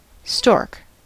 Uttal
IPA : /stɔrk/